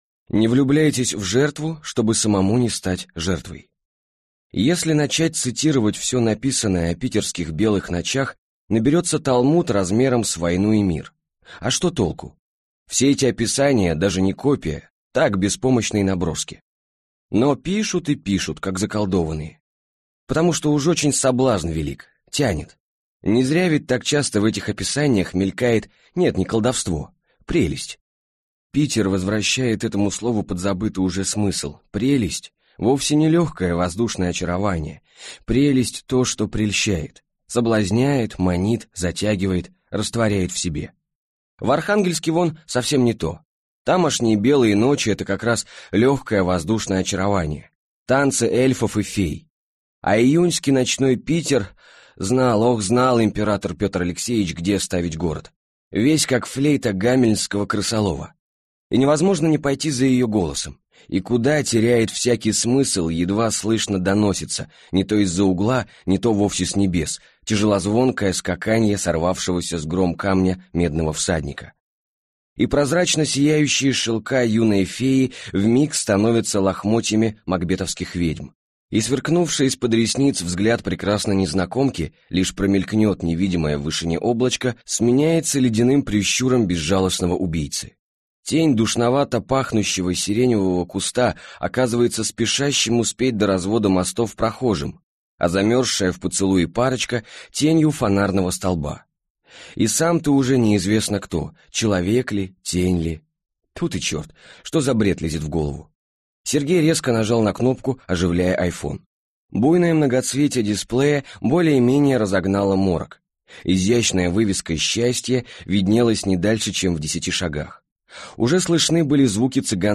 Аудиокнига Я тебя никому не отдам | Библиотека аудиокниг